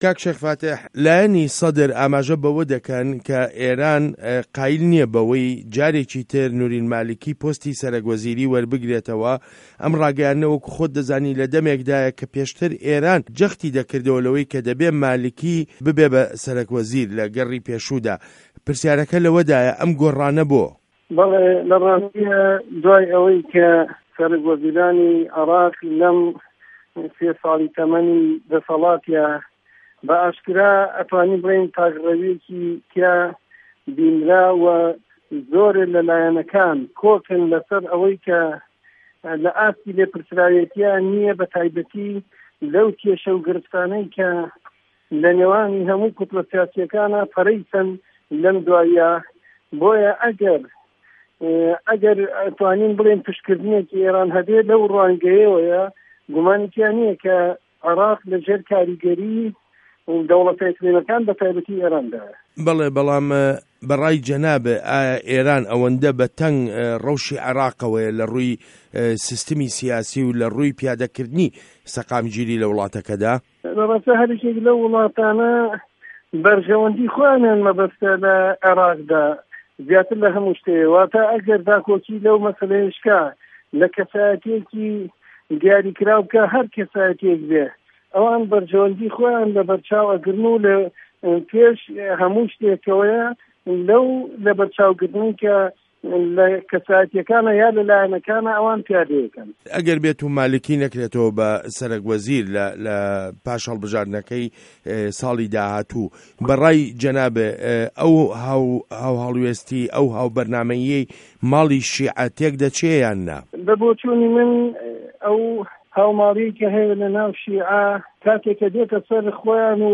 وتووێژ